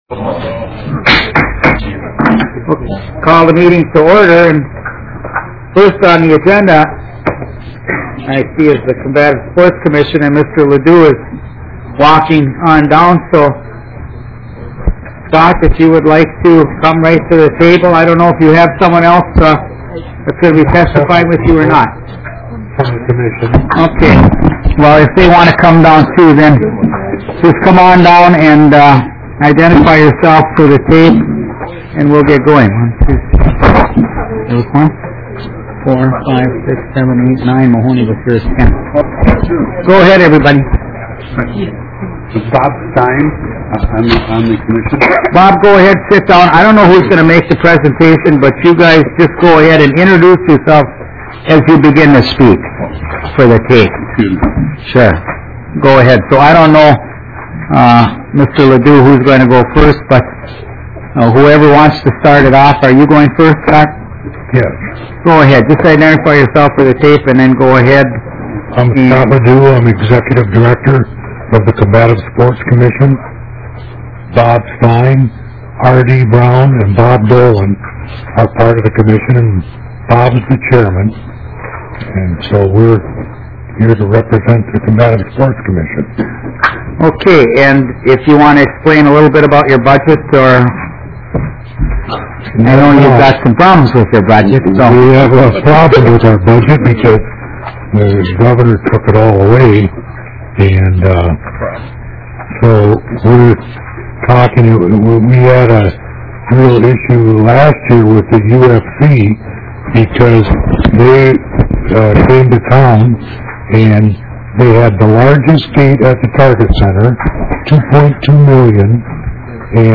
Higher Education and Workforce Development Finance and Policy Division TWELFTH MEETING - Minnesota House of Representatives